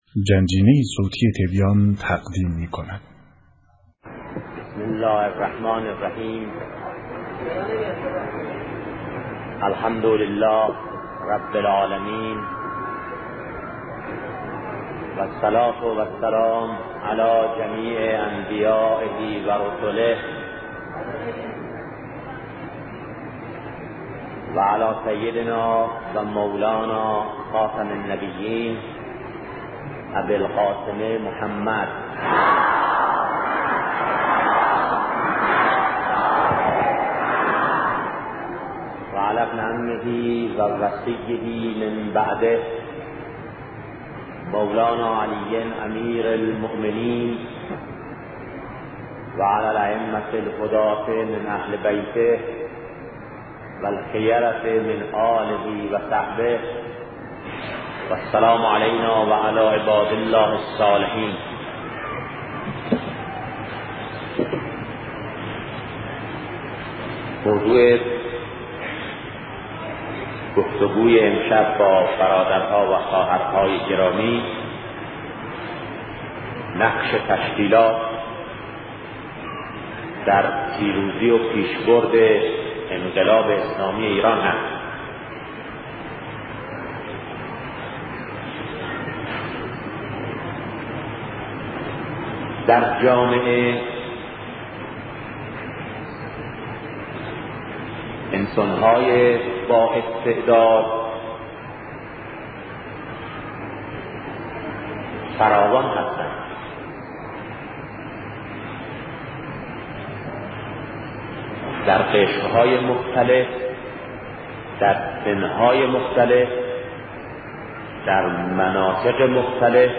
صوت سخنرانی شهید بهشتی_ با موضوع نقش تشکیلات در پیروزی و پیش‌برد انقلاب اسلامی - بخش‌اول